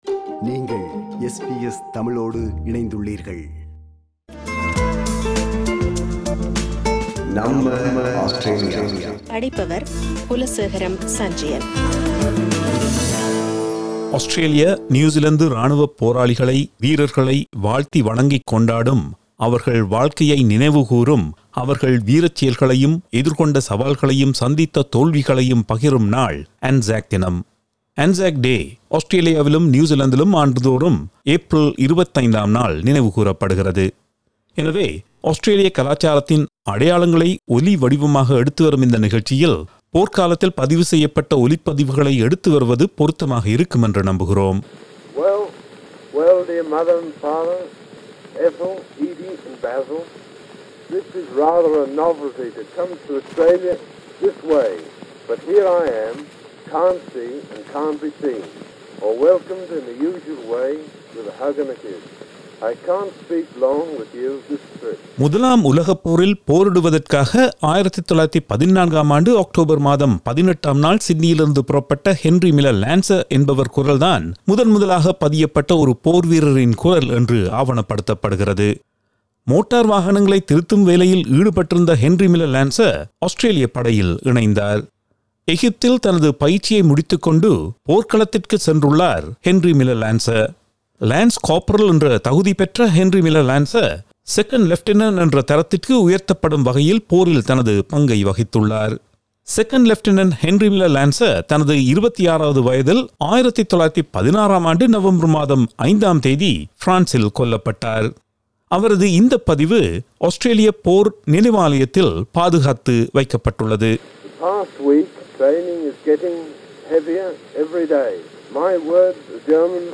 எனவே, ஆஸ்திரேலிய கலாச்சாரத்தின் அடையாளங்களை ஒலிவடிவாக எடுத்து வரும் இந்த நிகழ்ச்சியில், போர்க்காலத்தில் பதிவு செய்யப்பட்ட ஒலிப்பதிவுகளை எடுத்து வருவது பொருத்தமாக இருக்கும் என நம்புகிறோம்.